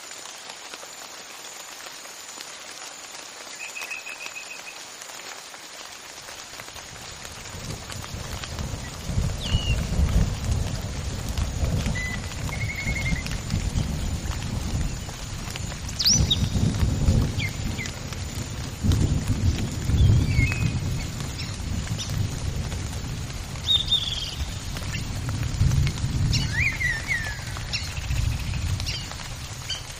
Free Ambient sound effect: Rain Forest Loop.
Rain Forest Loop
001_rain_forest_loop.mp3